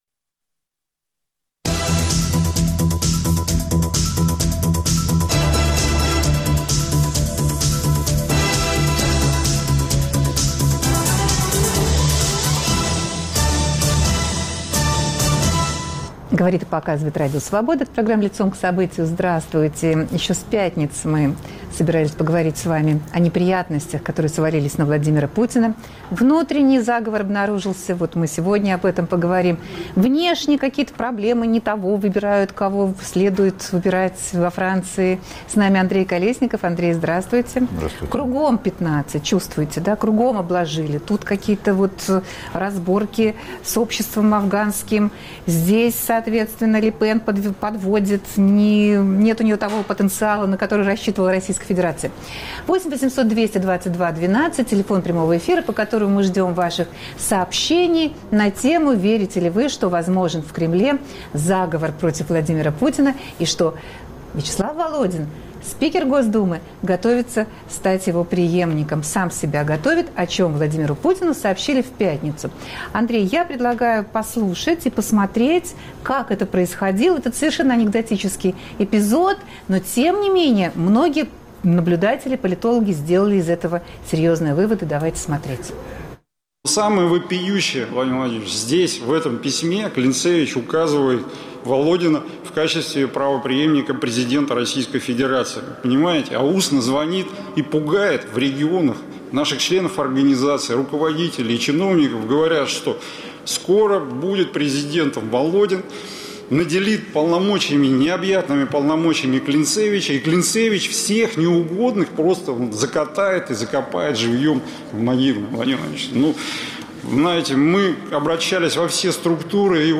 Владимиру Путину объявили, что спикер Госдумы Вячеслав Володин готовит себя в его преемники. Чего на самом деле можно ждать от этого опытного аппаратчика? Обсуждают аналитик Фонда Карнеги